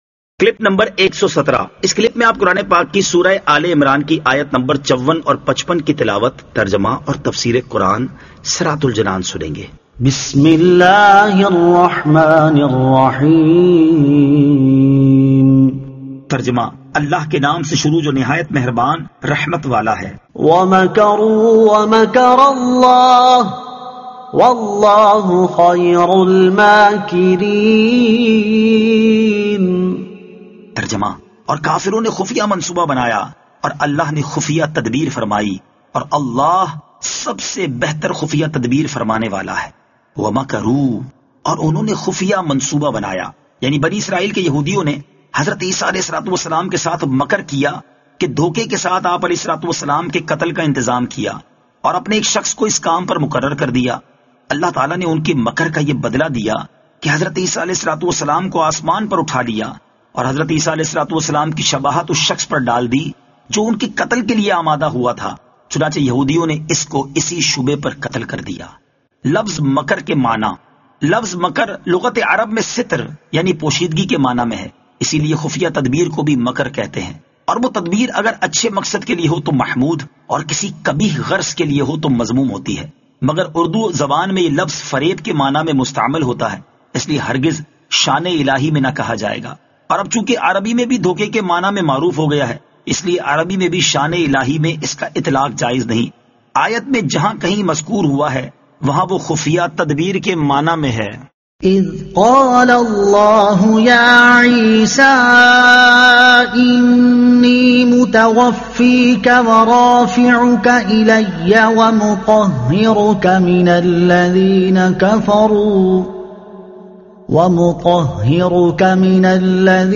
Surah Aal-e-Imran Ayat 54 To 55 Tilawat , Tarjuma , Tafseer